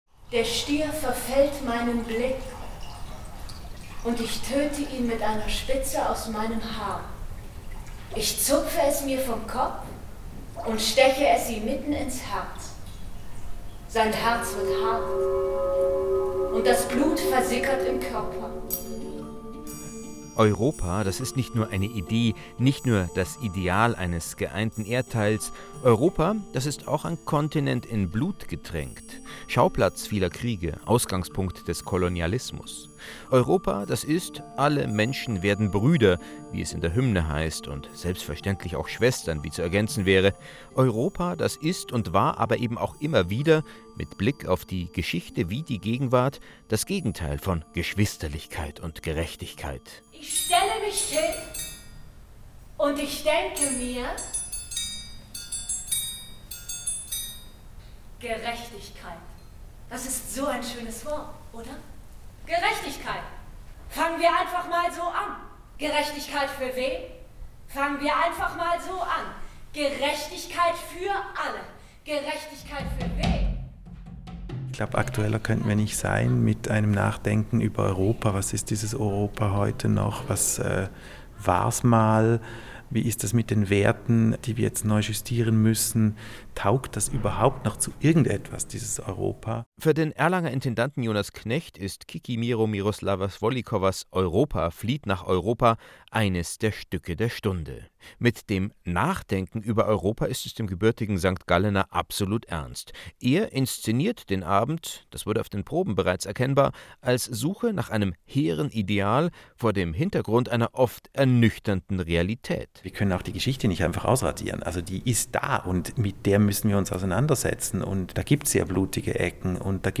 Vorbericht zum Stück auf Bayern 2